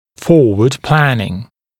[‘fɔːwəd ‘plænɪŋ][‘фо:уэд ‘плэнин]перспективное планирование